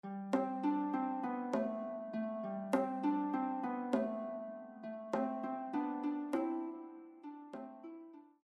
This very old song is danced in a bourrée.